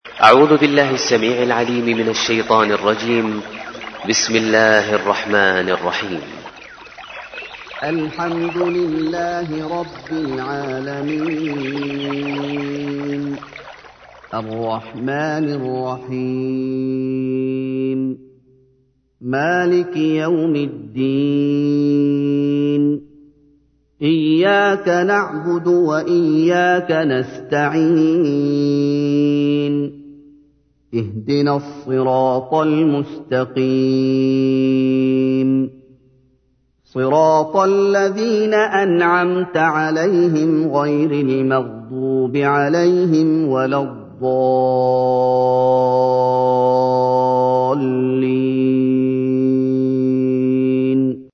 تحميل : 1. سورة الفاتحة / القارئ محمد أيوب / القرآن الكريم / موقع يا حسين